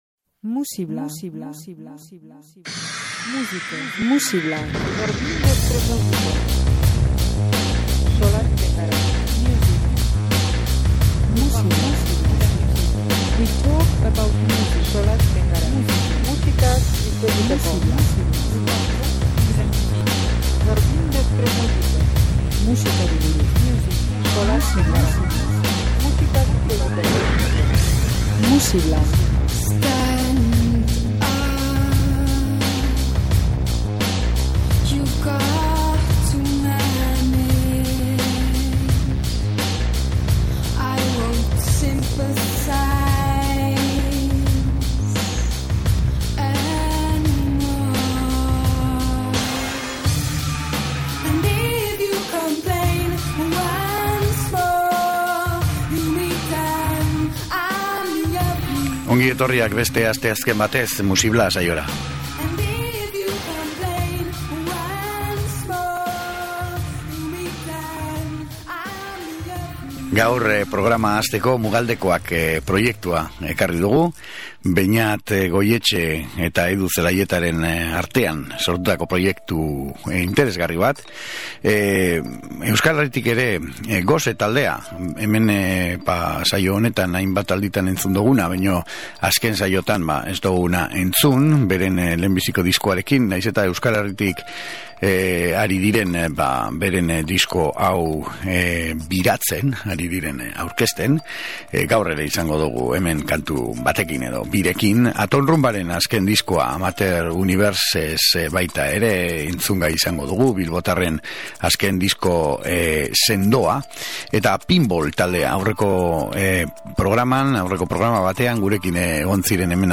sinkretismo musikala
soinu lisergikoak ere gurean izango dira.